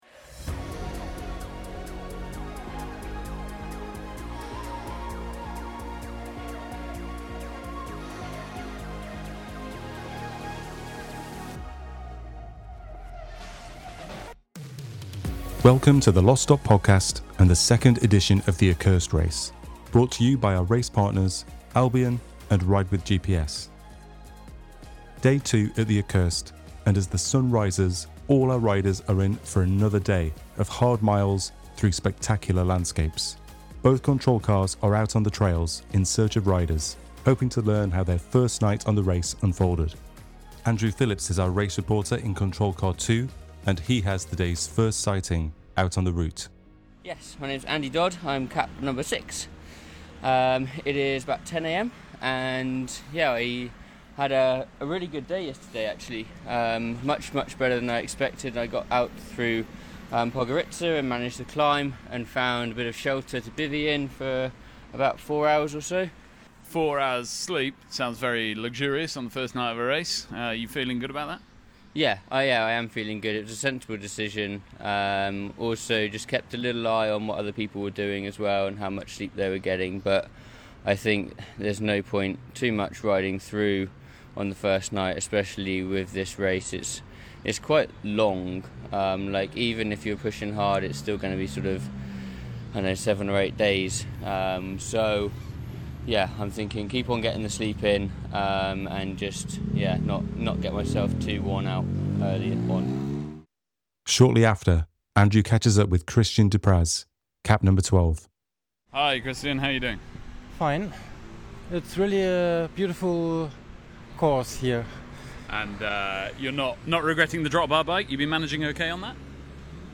Our media team gets the scoop, interviewing riders and hearing about their strategies, mistakes, and triumphs as the race continues to develop.
Hear what riders have to say for yourself, in our latest podcast episode recorded from the demanding route of The Accursed Race.